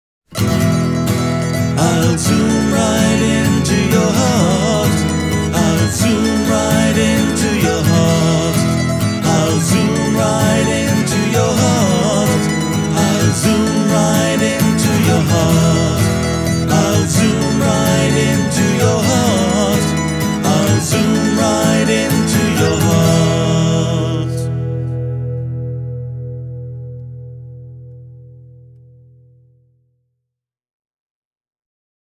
Äänitin kokeiluksi yhden akustisen demobiisin päällesoittoja käyttäen. Teräskielistä akustista kitaraa poimin talteen Zoomin XY-moduulilla (90 asteen kulma), minkä jälkeen äänitin kaksi lauluosuutta ja kaksi mandoliiniraitaa dynaamisella Shure SM57 -mikrofonilla.
Miksasin biisin ensin H6:n omalla mikserillä, minkä jälkeen vein stereomiksauksen Garagebandiin, jossa poistin sisäänlaskun ja lisäsin hieman yleiskaikua:
Tämän jälkeen vein biisin yksittäiset raidat Garagebandiin, ja miksasin biisiä uudelleen sekvensserillä: